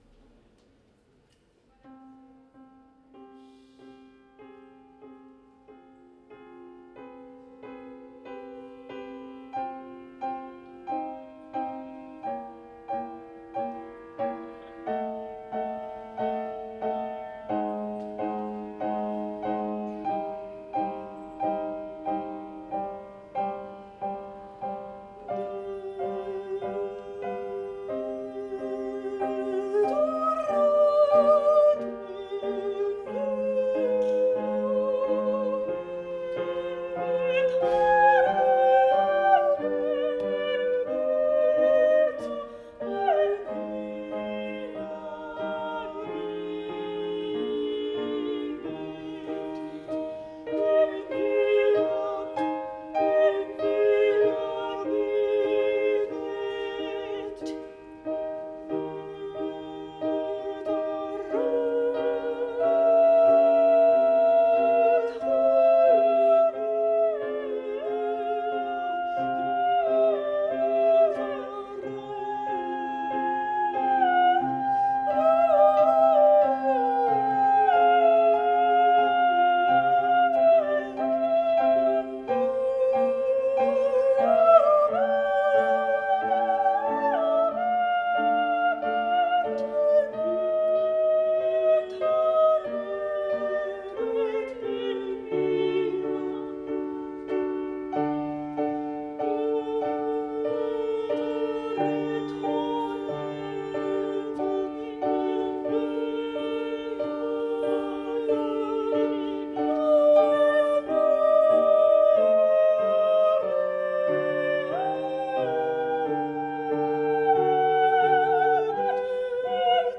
Performances in 2015
International Summer Academy of Music, Nice, France